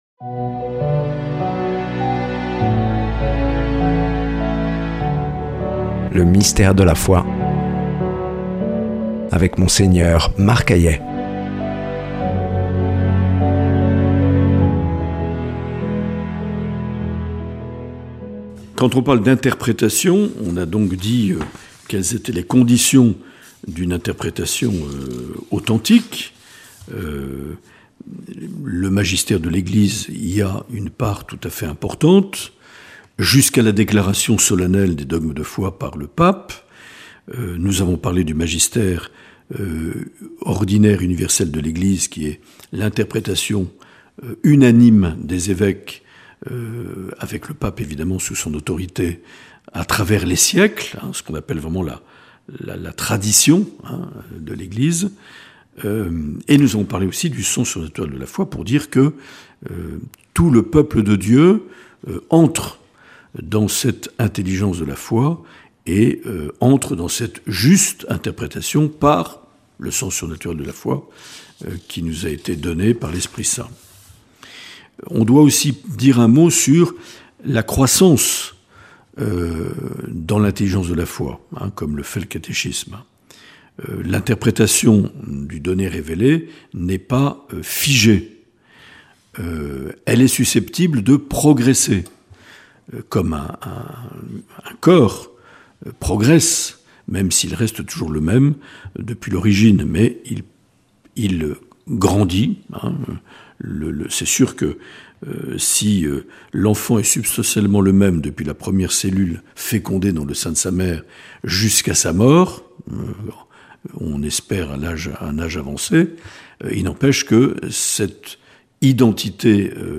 Présentateur(trice)